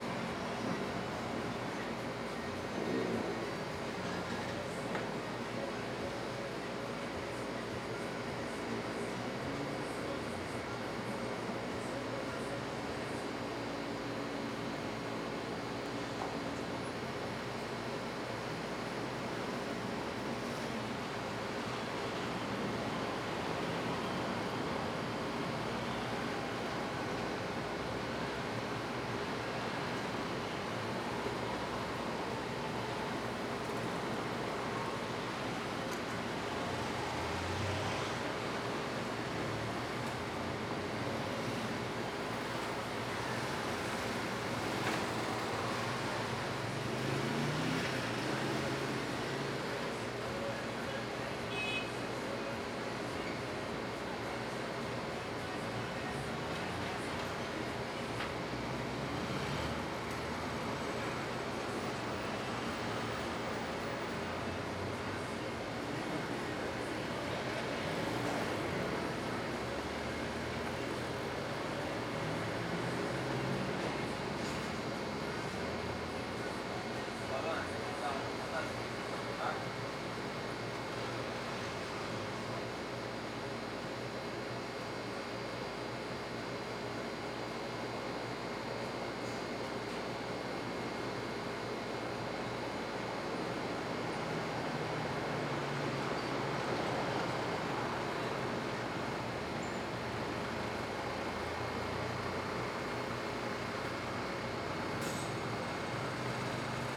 CSC-08-008-LE - Distribuidora vazia refrigerador, musica longe, passam algumas motos.wav